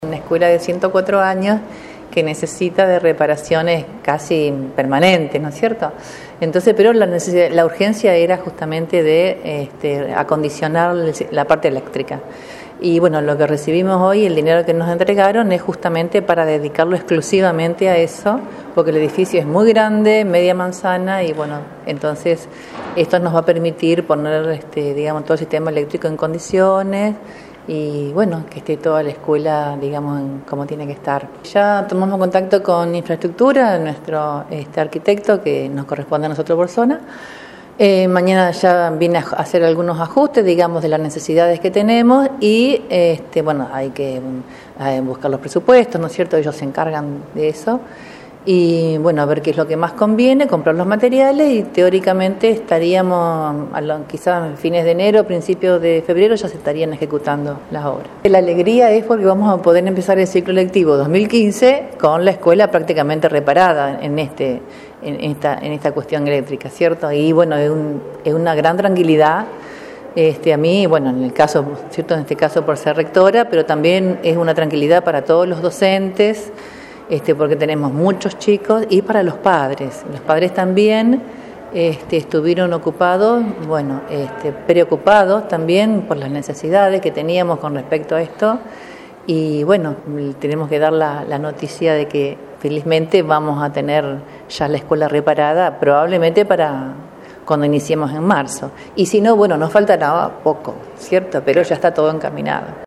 Palabras de autoridades escolares.